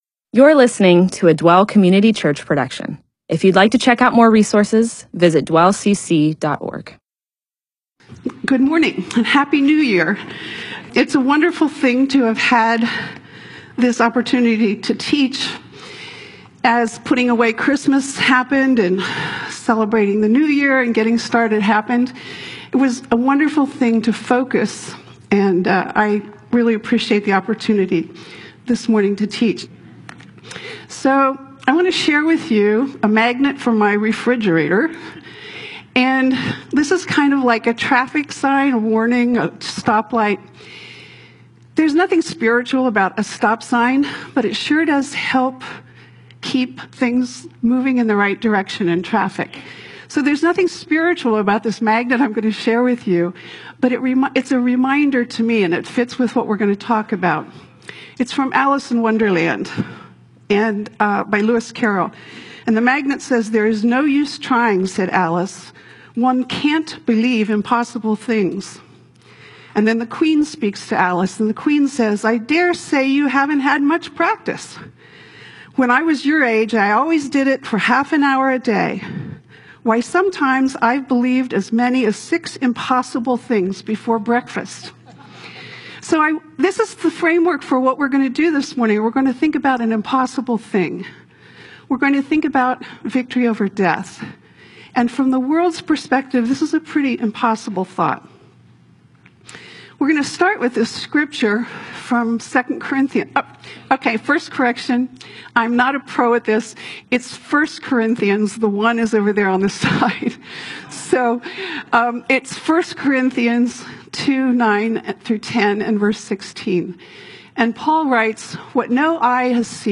MP4/M4A audio recording of a Bible teaching/sermon/presentation about 1 Corinthians 2:9-10; 1 Corinthians 2:16.